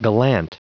Prononciation du mot gallant en anglais (fichier audio)
Prononciation du mot : gallant